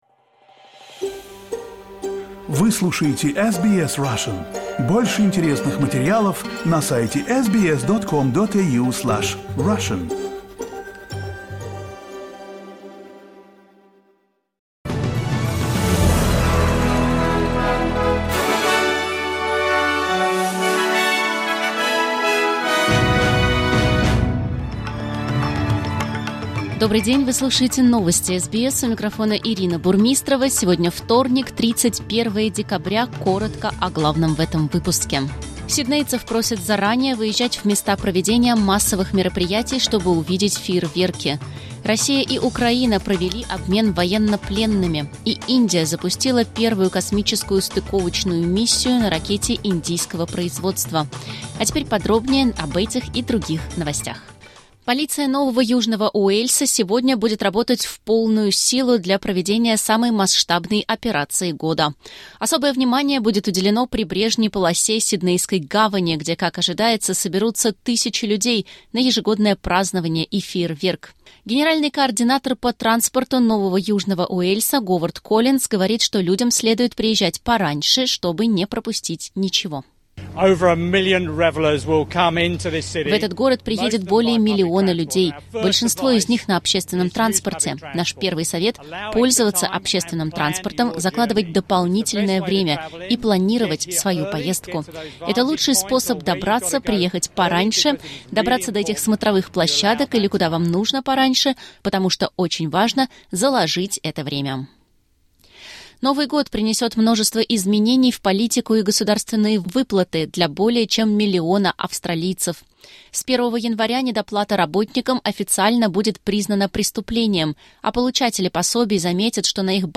Новости SBS на русском языке — 31.12.2024